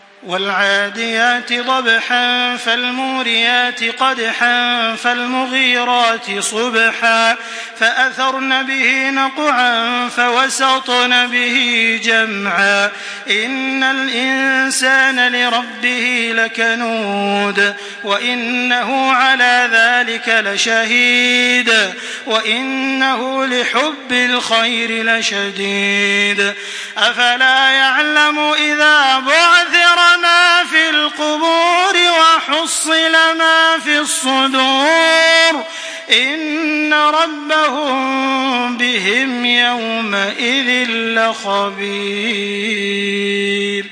Surah Al-Adiyat MP3 by Makkah Taraweeh 1425 in Hafs An Asim narration.
Murattal